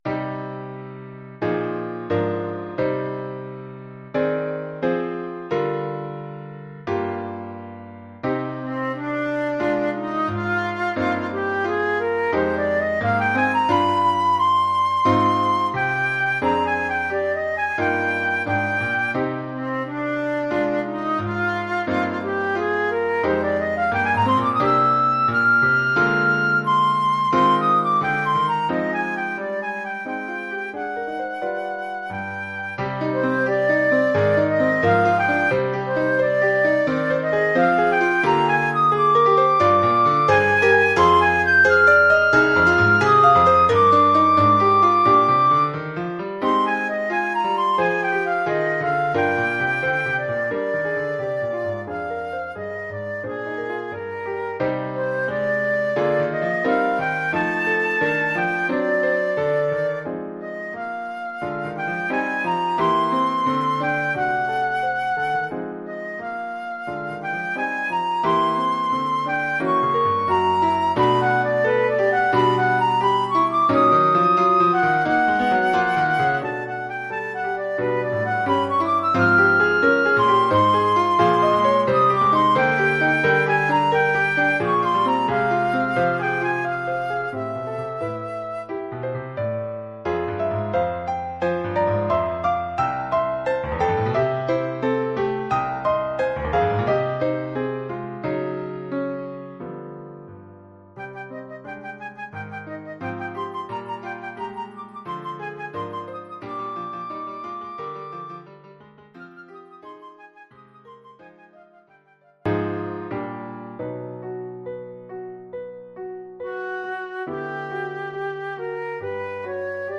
Oeuvre en 3 mouvements,
1er mouvement : Deciso (durée 2'46’’).
2e mouvement : Calmato (durée 1'55’’).
3e mouvement : Vivace (durée 1'54’’).